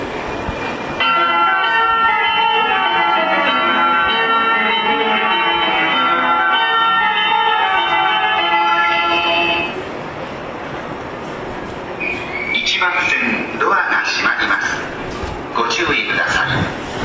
発車メロディをかき消してくれます。
ドアが開いた瞬間に発車メロディが鳴り出すのもこの駅ならではです。